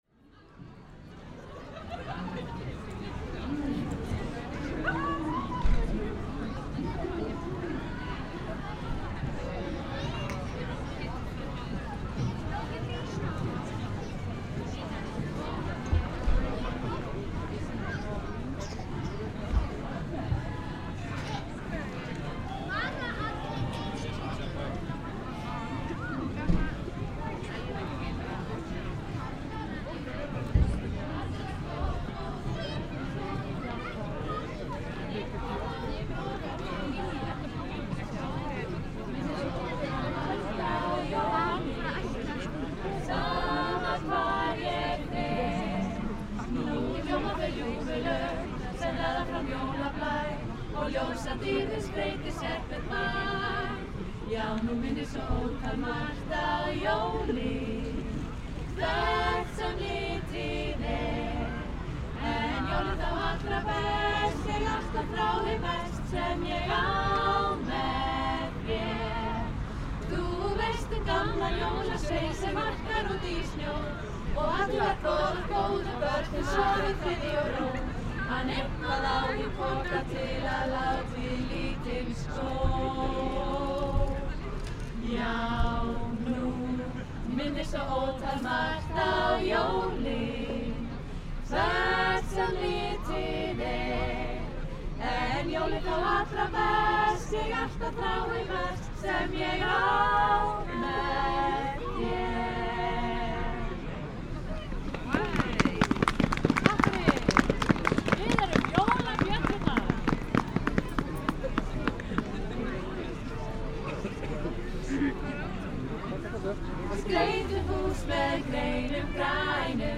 Just 30 second after I start the recording, I heard beautiful singing voices. There were six singers of female song group „Jólabjöllurnar“. I placed my self around 4 meters from the singers. Behind me was the audience on their walk who stopped for a moment to listen. The heavy background noise is mostly from car traffic, as usual. This recording is captured with DIY binaural microphone rig which I just finished to prepare several hours before the recording.
I noticed some audible time error between left and right channel in 30-40° around the rig which means the rig needs some changes in the future.
This was recorded on Lækjatorg square in Reykjavik town center between 9 – 10 pm. Quality open headphones are recommended while listening at mid level.